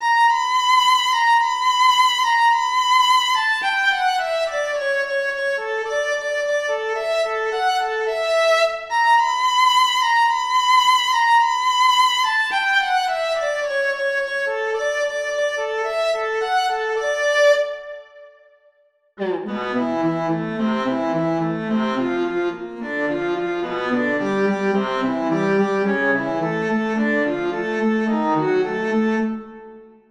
Biamonti 222 - Rondo-Presto per Quartetto d'archi dal Mikulicz: 6)Erste Note: Strich bis zur d2-Linie aber cis2-Kopf, dritte Note h1.
Per me questo schizzo è decisamente in si; Infatti troviamo subito un la# (non replicato ma sottinteso nelle altre 2 misure successive ma con l’indicazione di ♮ sulla a misura 3) e poi troviamo un do ♮ nella seconda parte subito a misura 2. Il primo dei 2 schizzi è decisamente un Tema con 2 semifrasi di 8 misure ciascuna in aperto-chiuso. La seconda parte invece è una progressione ascendente modulante che porta da un ipotetico la- a mi-.
Biamonti-222-Rondo-Presto-per-Quartetto-darchi.mp3